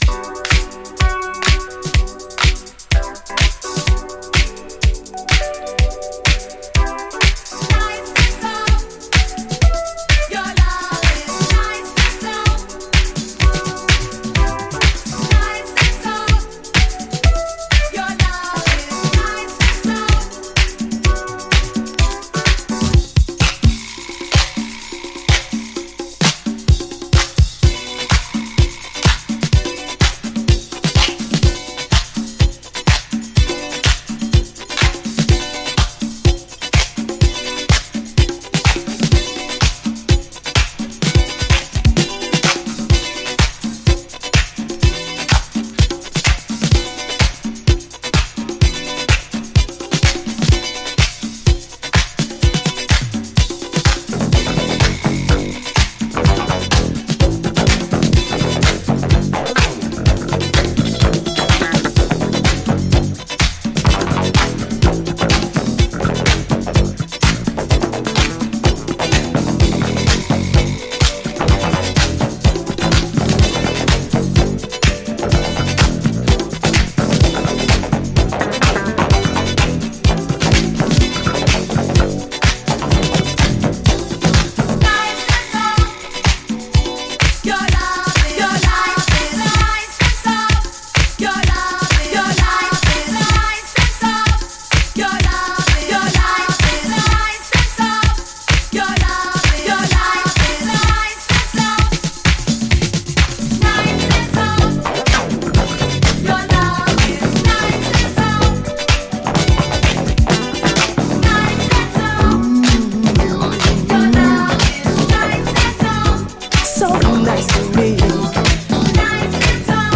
DISCO
「(SPECIAL DISCO MIXER)」/「(UPTOWN VERSION)」